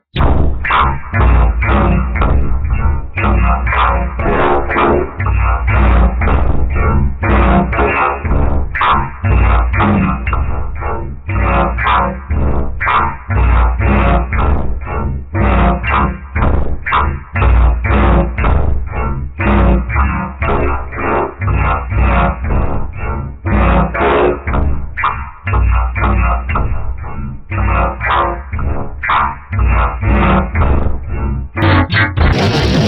shitcore